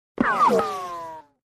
Tiếng Đạn Bay vèo…. kéo dài
Đánh nhau, vũ khí 414 lượt xem 04/03/2026
Hiệu ứng âm thanh tiếng đạn bay vèo.... kéo dài, tiếng viên đạn bay ngang qua vụt với tấc độ rất cao khi bắn ra khỏi nòng súng mp3 này miễn phí cho việc edit làm phim, ghép video, chỉnh sửa video.